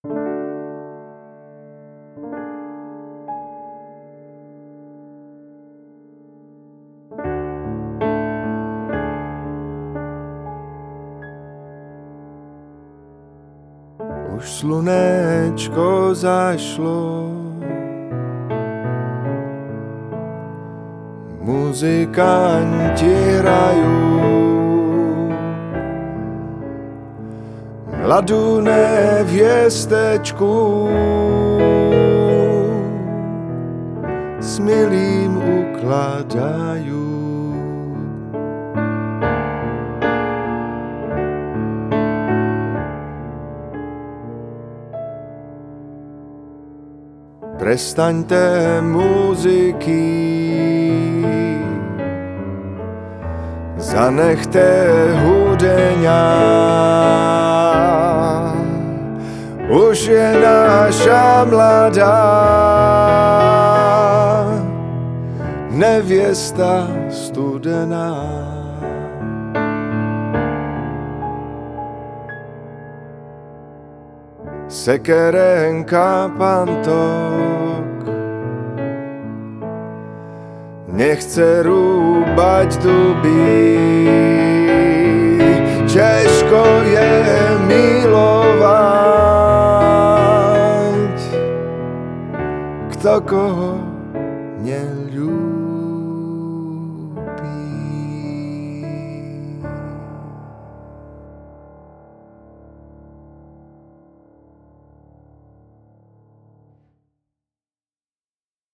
Hudba pro divadlo
moravská lidová píseň, tramskripce pro hlas a piano